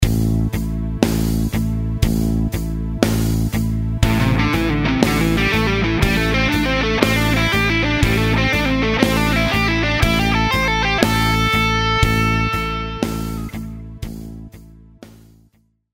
Guitar Lick 5 – Position 1, A minor pentatonic lick.
Audio Sample Lick 5 Slow – 60BPM
Lick5_60bpm.mp3